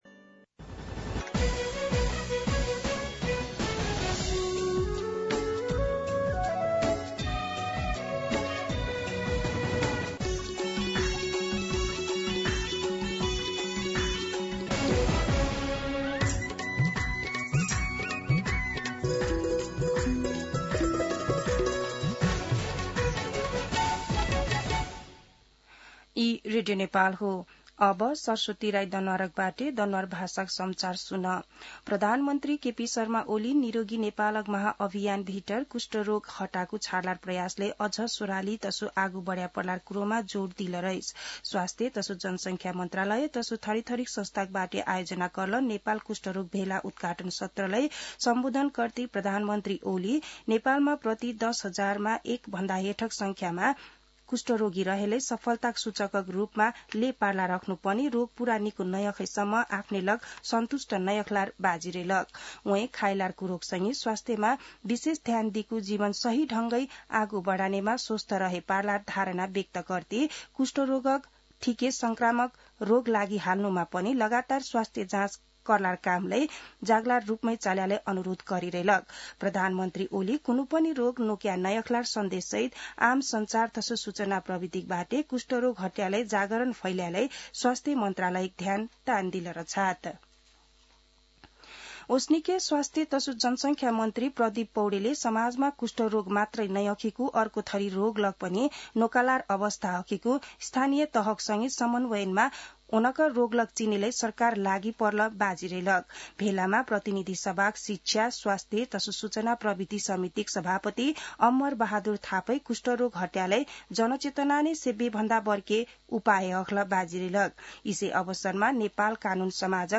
दनुवार भाषामा समाचार : २५ वैशाख , २०८२
Danuwar-News-1.mp3